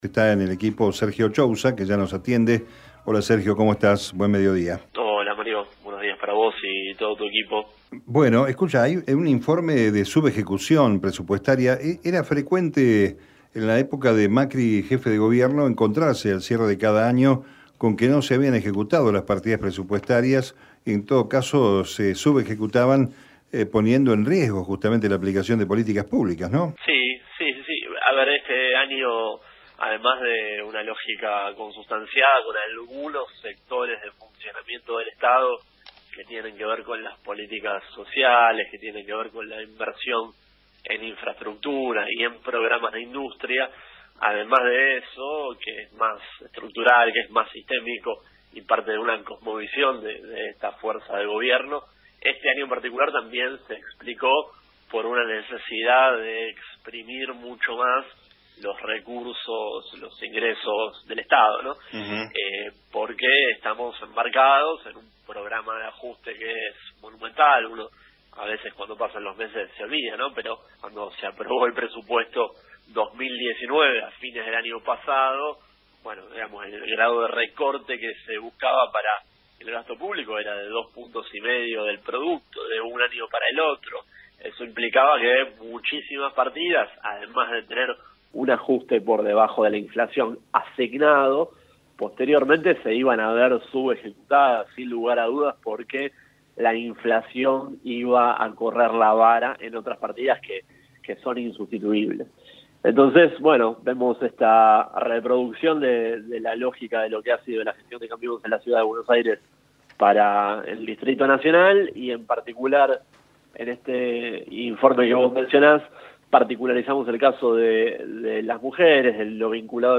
Hoy en Meridiano Nacional entrevistamos